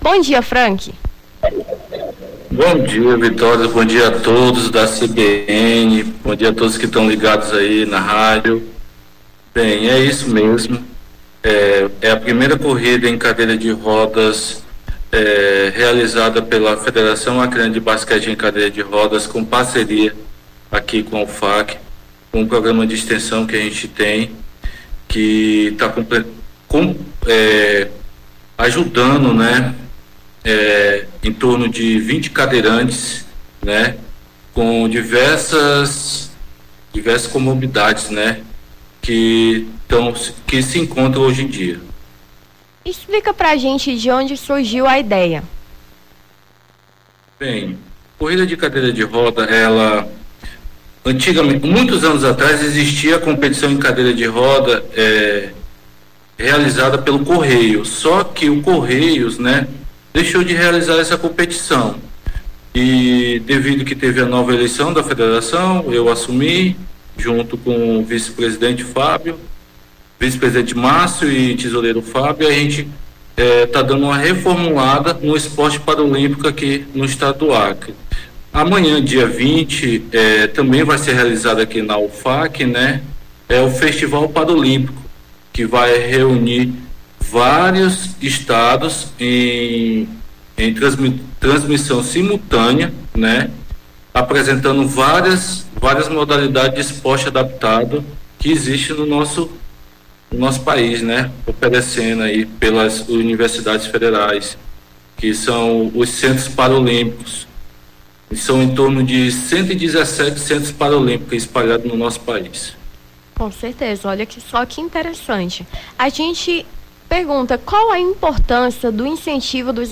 Nome do Artista - CENSURA - ENTREVISTA (CORRIDA DE CADEIRA DE RODAS) 19-05-23.mp3